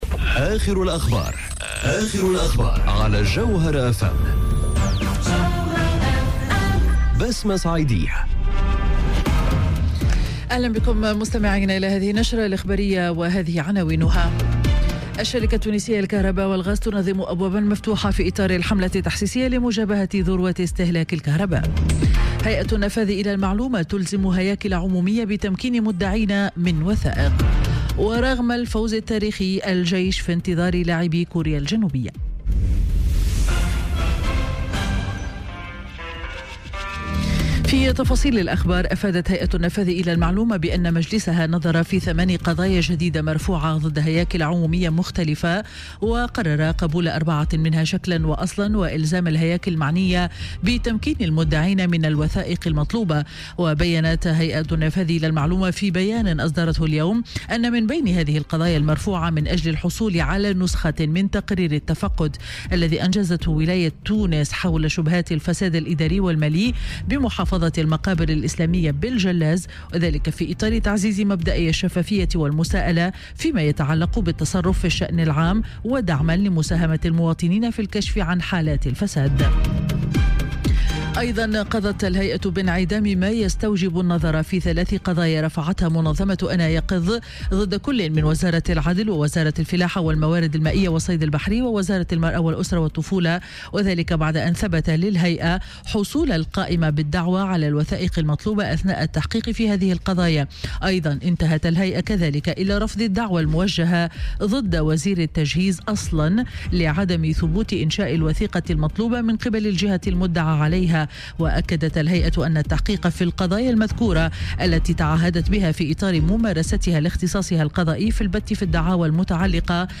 نشرة أخبار منتصف النهار ليوم الجمعة 29 جوان 2018